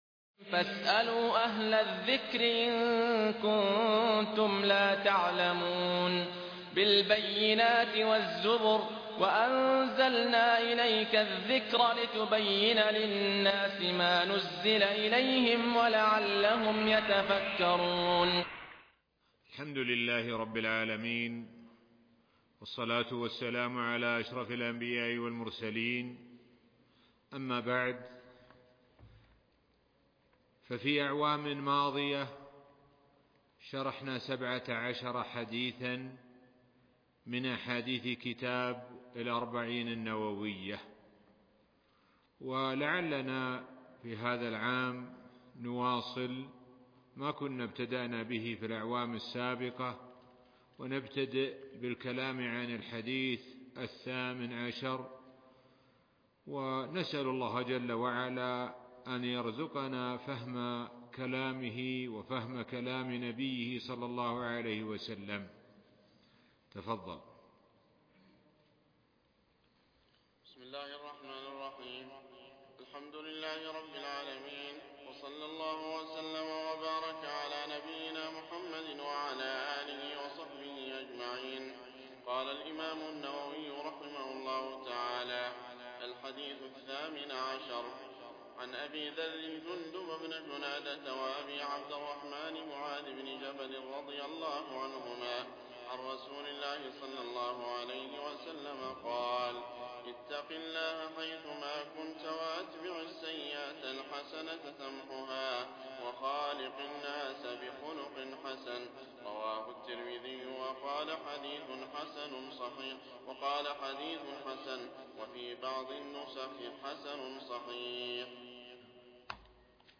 الأربعون النووية شرح الشيخ سعد بن ناصر الشتري الدرس 1